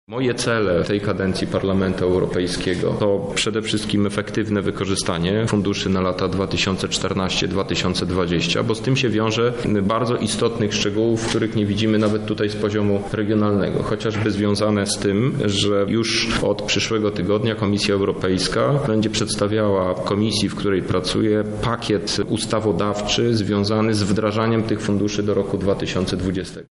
Na pierwszej konferencji po wyborach były marszałek województwa lubelskiego tłumaczył, że „lubelska polityka dogoniła go szybciej niż się spodziewał”.